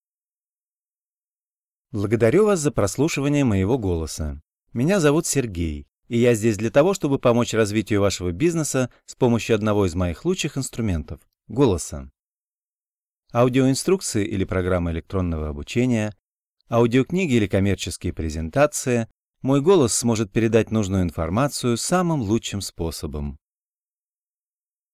I am native Russian speaker with a regular Moscow accent.
General demo
Middle Aged